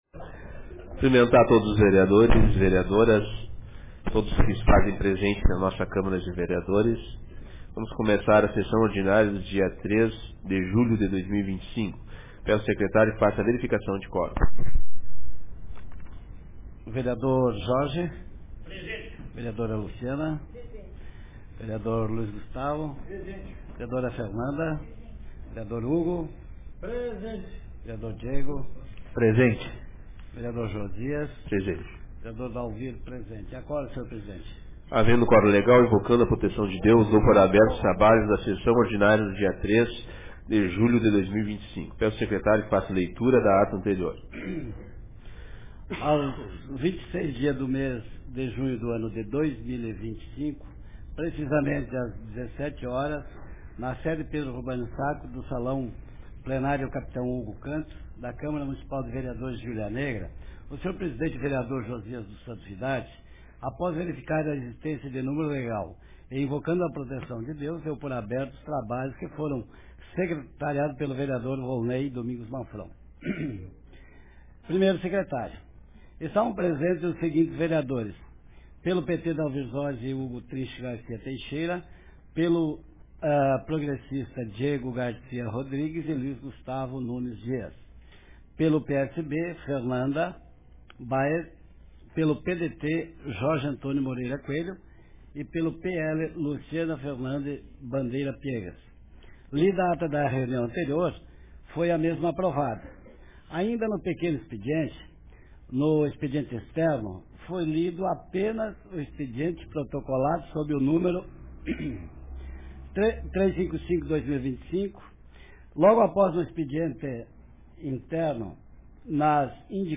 Sessão Ordinária da Câmara de Vereadores de Hulha Negra Data: 03 de julho de 2025